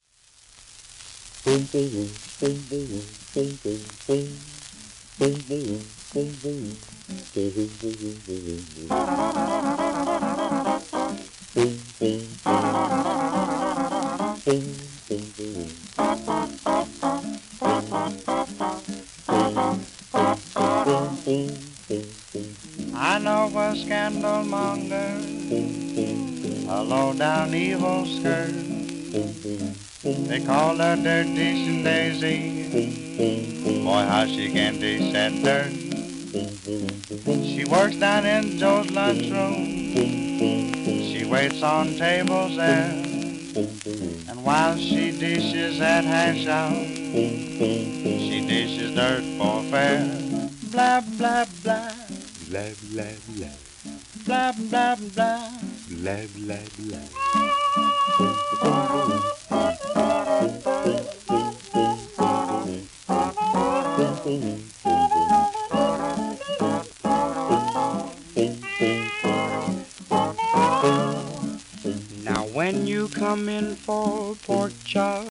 盤質:B+/B *やや溝あれ、キズ、スレ
1932頃の録音
ギター以外は全て人の声によるもの